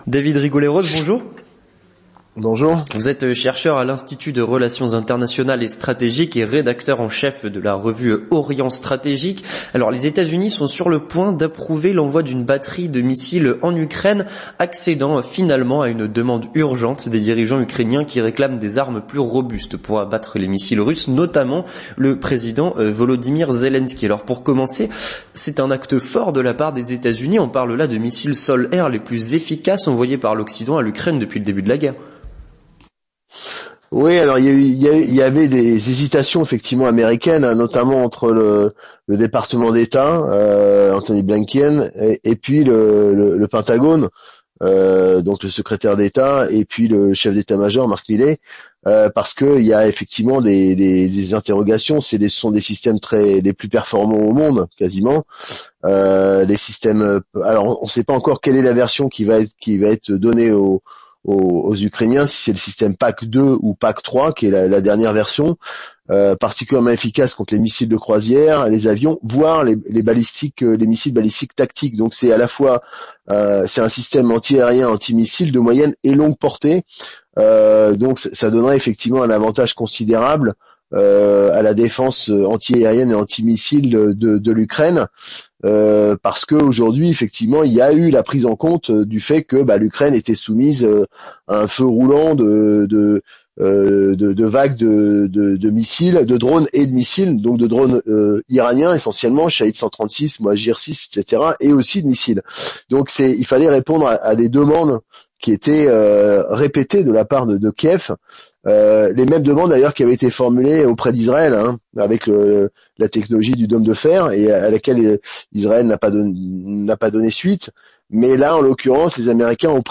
L'Entretien du Grand Journal